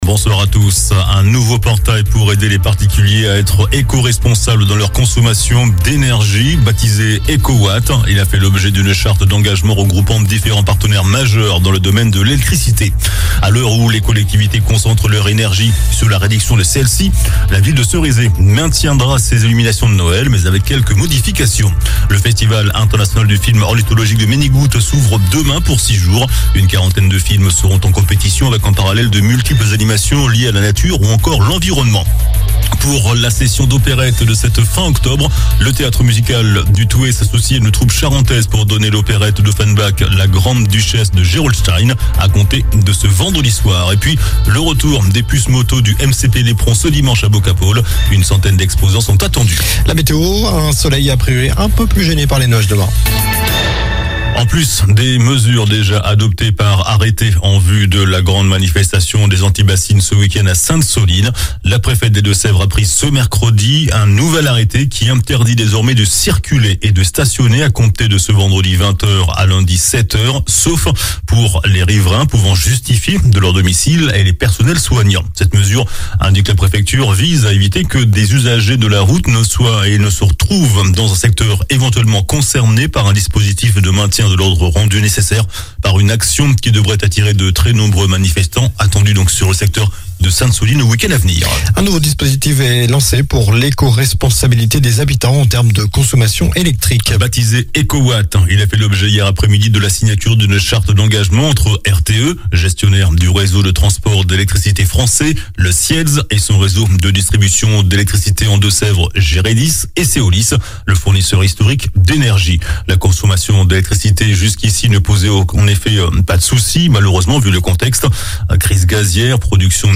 JOURNAL DU MERCREDI 26 OCTOBRE ( SOIR )